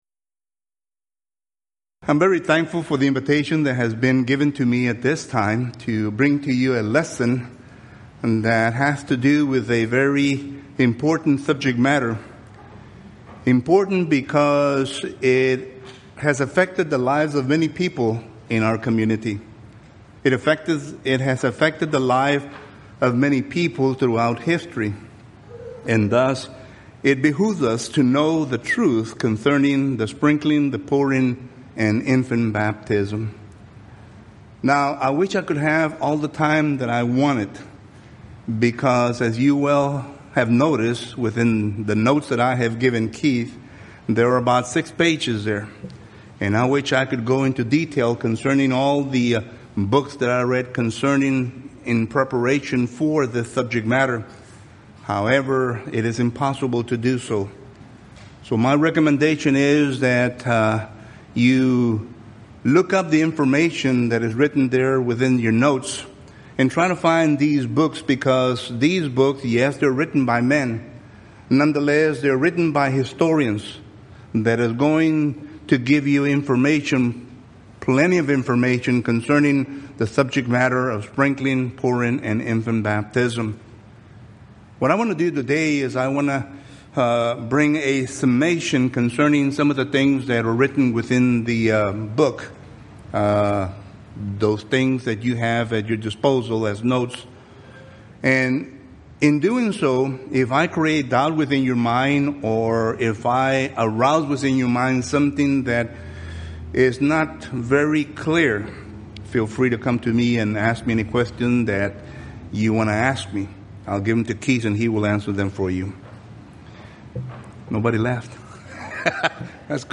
Event: 2015 South Texas Lectures
lecture